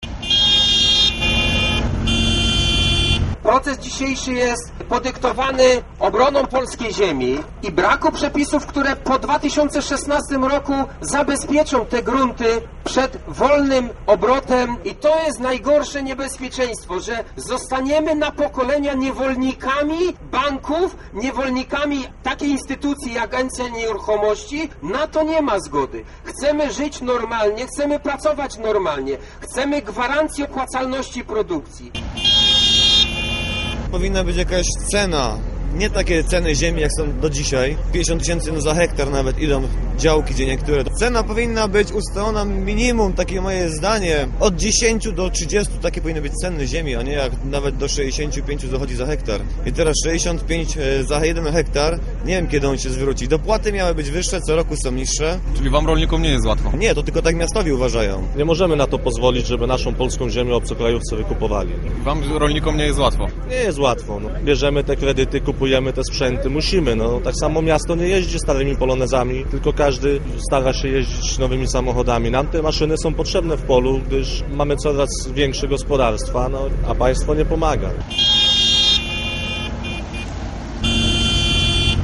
wolnym obrotem. To jest najgorsze niebezpieczeństwo. Nie ma na to zgody. Chcemy żyć i pracować normalnie, a państwo nam tego nie ułatwia - mówili rolnicy uczestniczący w proteście.